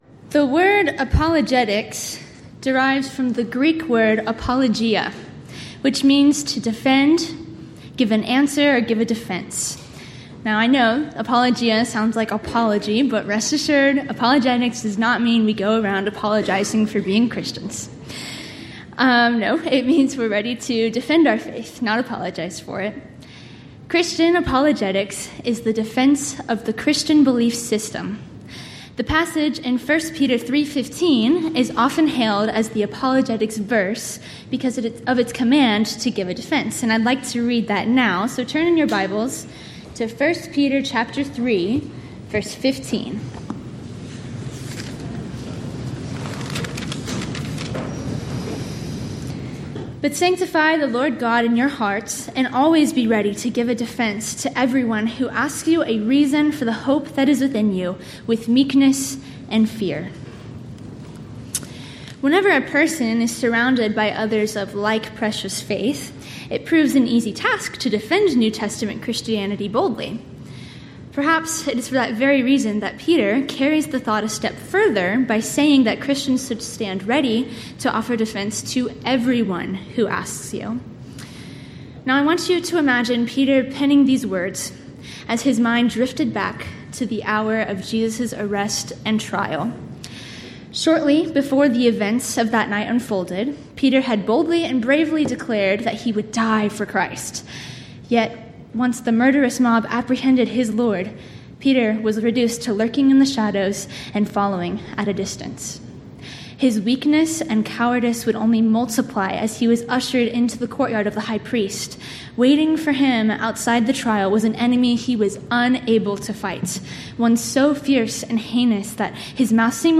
Title: Friday PM Devotional
Event: 13th Annual Texas Ladies in Christ Retreat Theme/Title: Studies in 1 Corinthians